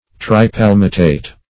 Search Result for " tripalmitate" : The Collaborative International Dictionary of English v.0.48: Tripalmitate \Tri*pal"mi*tate\, n. [Pref. tri- + palmitate.]
tripalmitate.mp3